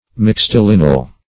Search Result for " mixtilineal" : The Collaborative International Dictionary of English v.0.48: Mixtilineal \Mix`ti*lin"e*al\, Mixtilinear \Mix`ti*lin"e*ar\, a. [L. mixtus mixed (p. p. of miscere to mix) + E. lineal, linear.]
mixtilineal.mp3